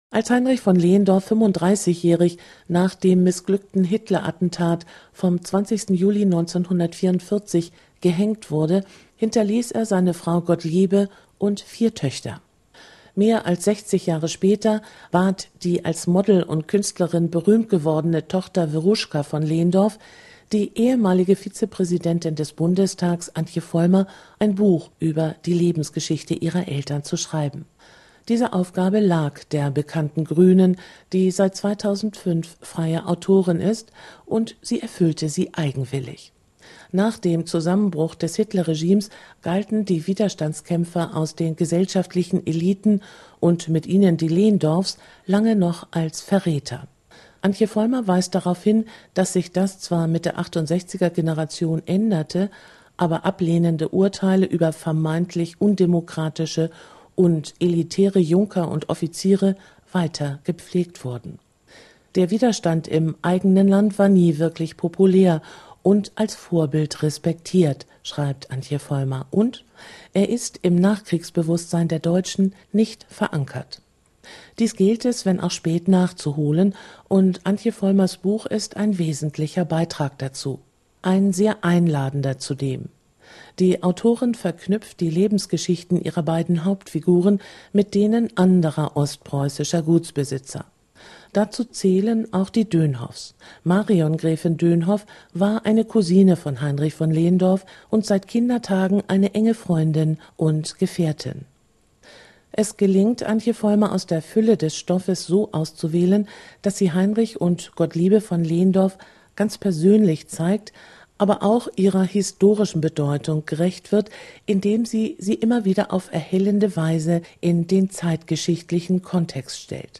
swr2-die-buchkritik-antje-vollmer-doppelleben.6444m.mp3